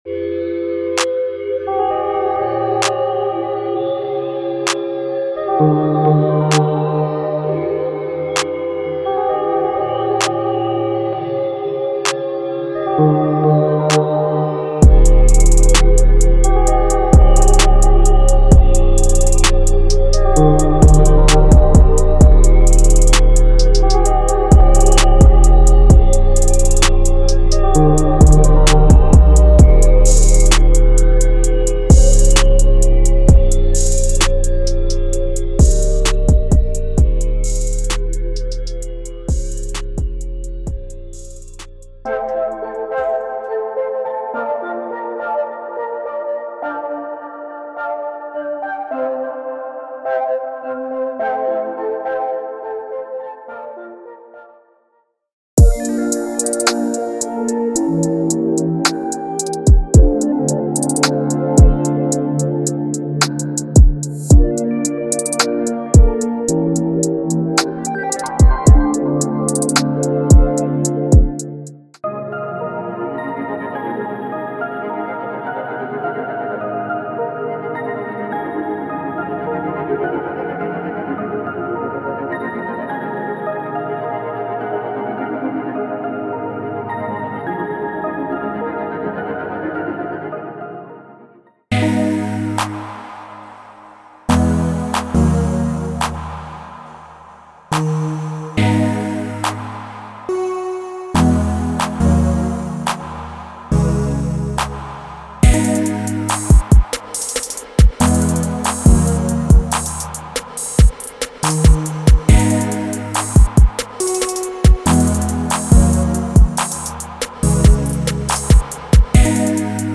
Sound Kit
Drum Kit (808s, Kicks, Claps, Snares, Hats, and Percs)